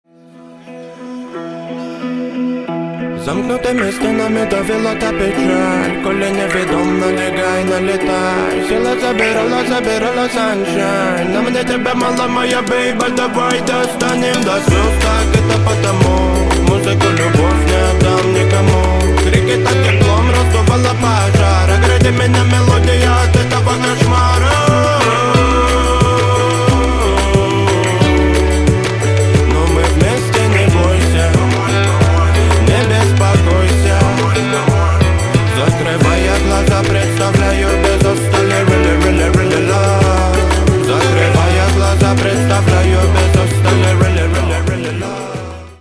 ритмичные
Хип-хоп
спокойные
растаманские